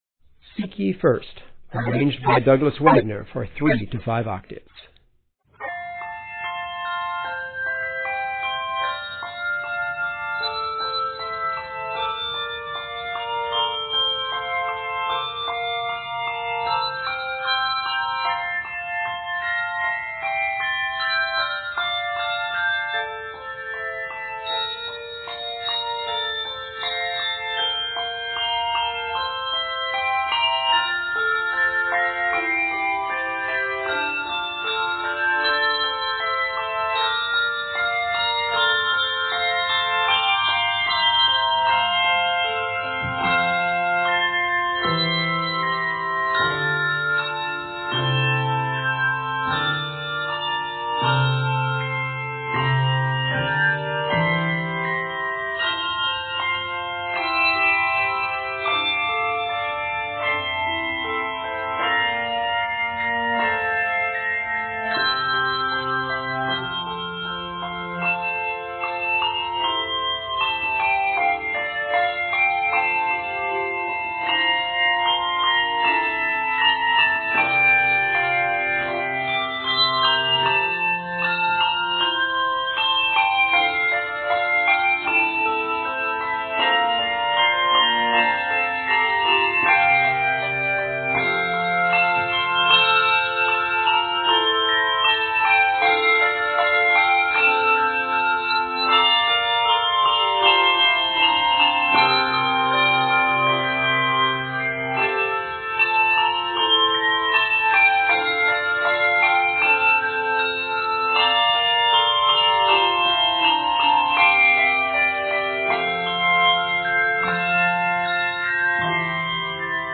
set for bells